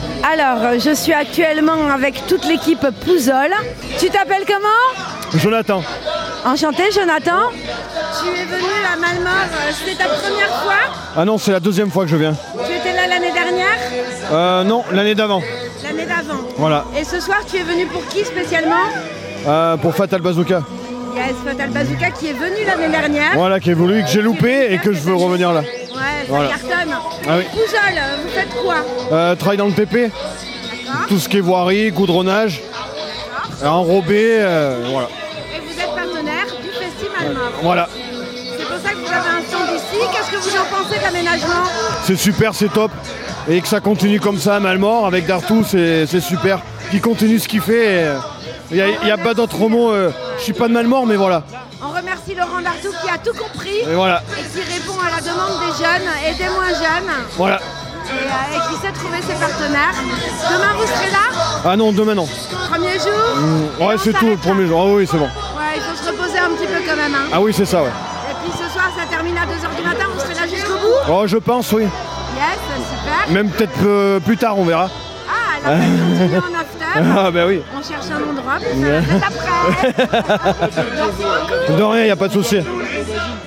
Interviews Festi'malemort email Rate it 1 2 3 4 5